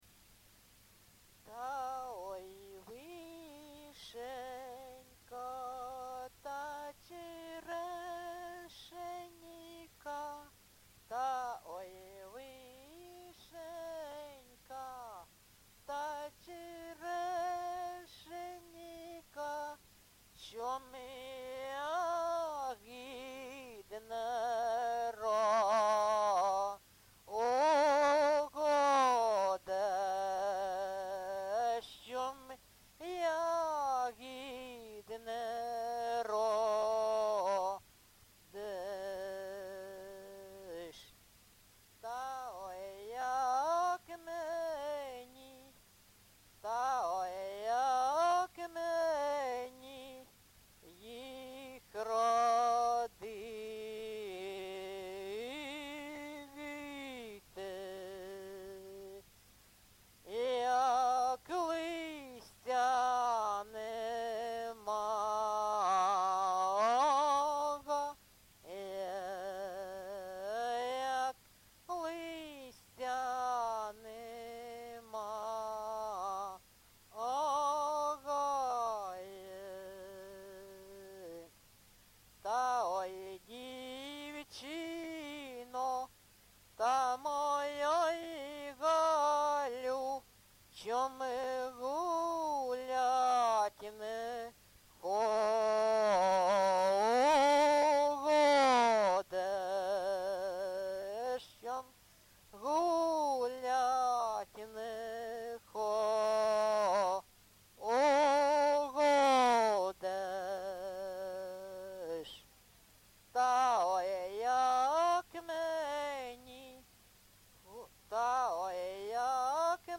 Місце записум. Ровеньки, Ровеньківський район, Луганська обл., Україна, Слобожанщина